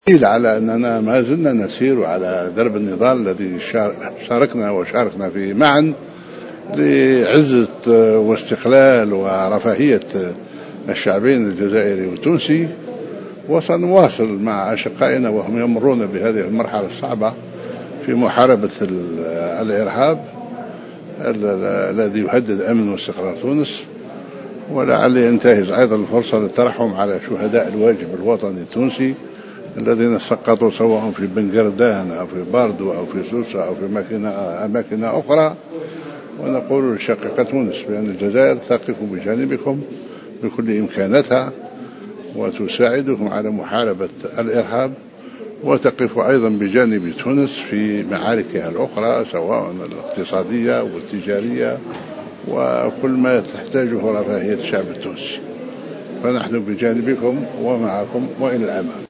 وأضاف في تصريح لمراسل "الجوهرة أف أم" خلال تظاهرة وطنية لإحياء الذكرى 60 لعيد الاستقلال بمقر مؤسسة الأرشيف الوطني أن بلاده ستواصل دعم تونس في مختلف المجالات الأخرى الاقتصادية والتجارية.